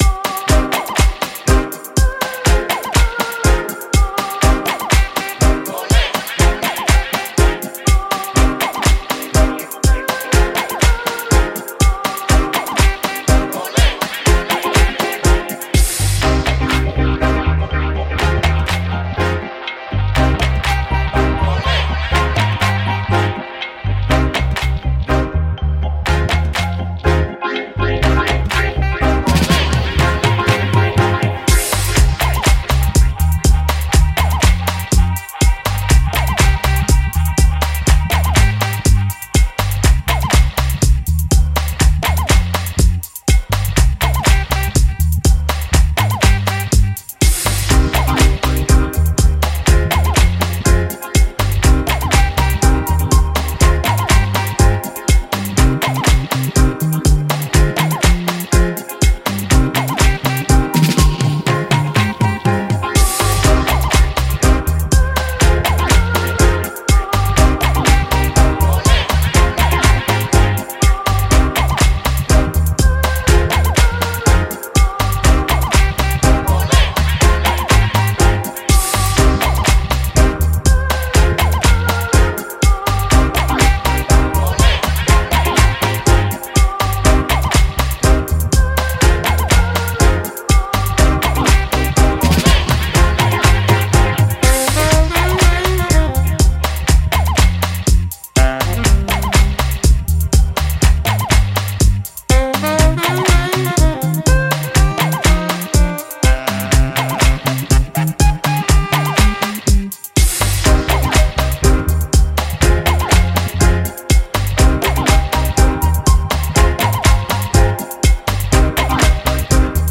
Genre:Dub
本作はヘヴィウェイトベースミュージック向けに作られた、深く力強いダブ制作パックです。
122 BPM、Cmキーで提供され、クラシックなレゲエグルーヴと現代的なプロダクションパワーを融合しています。
600MBのループ＆ワンショット – ドラムヒット、ベースライン、スカンク、FXなど、精密にカットされた要素。
デモサウンドはコチラ↓
Tempo Range: 122 bpm
Key: Cm